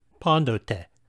Pronunciation Note: When Tau (τ) follows a Nu (ν), such as in the word πάντοτε, the Tau is pronounced like the d in den, rather than like the t in star (its primary sound).